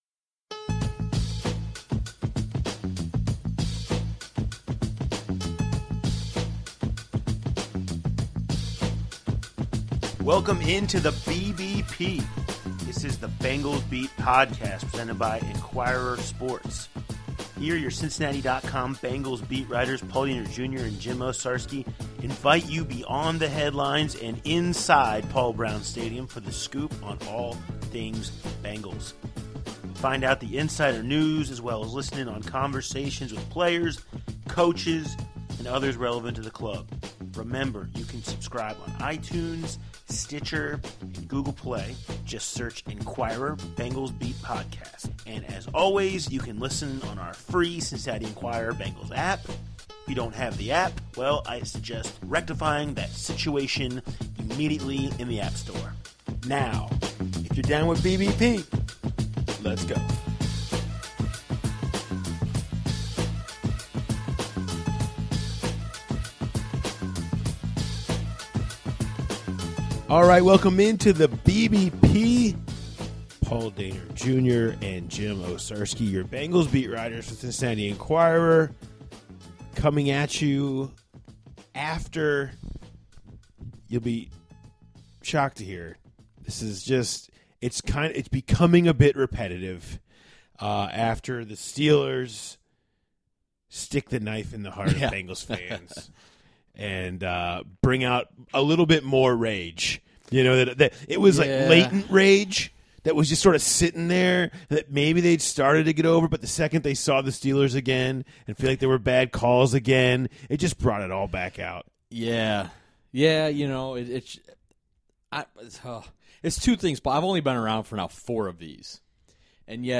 They delve into the running game, personnel decisions, the return reality of Tyler Eifert and take on all your officiating complaints. Hear from offensive coordinator Ken Zampese, wide receiver Tyler Boyd and defensive back Adam Jones.